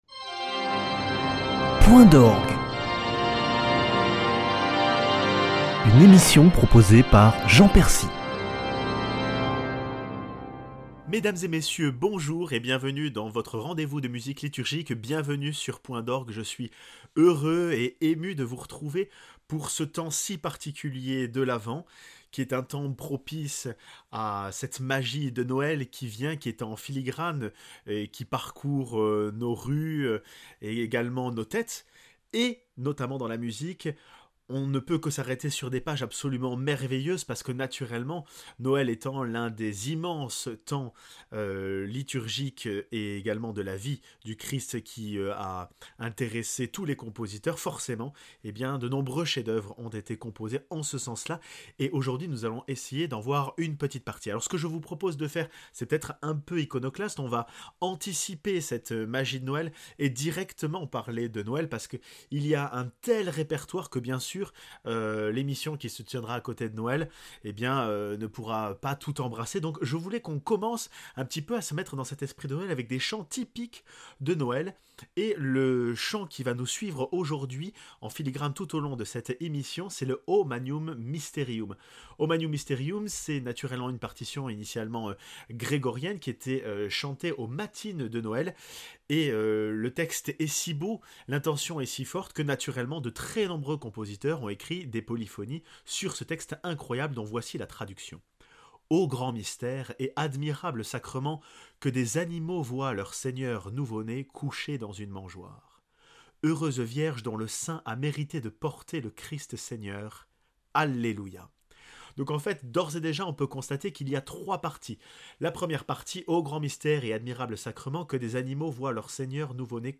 O magnum mysterium est un chant qui relate la nuit de Noël. Et au milieu de cette nuit, les trompettes résonnent !